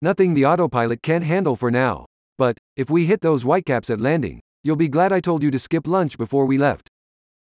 Devlog #4: Prototyping Dialogue with Google Text-to-Speech
The Text-to-Speech product is a Cloud API which delivers great-sounding voice clips from the text strings you provide.
And here's a sample of what gets generated:
speedh-to-text-sample.wav